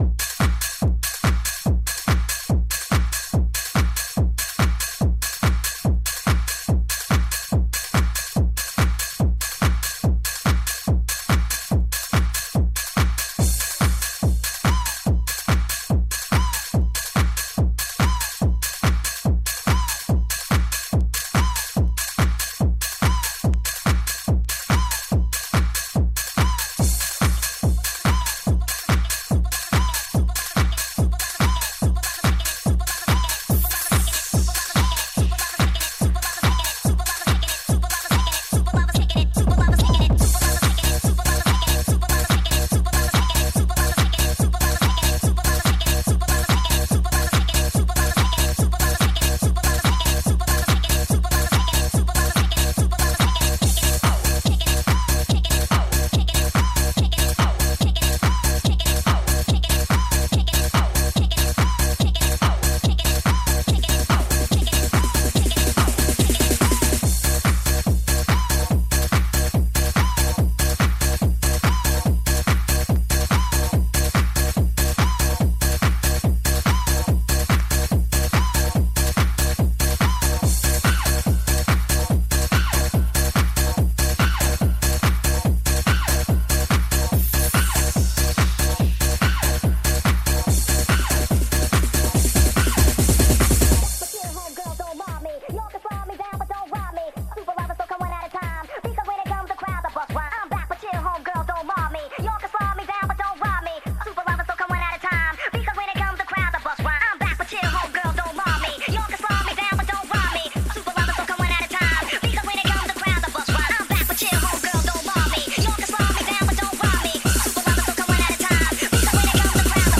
Жанр: House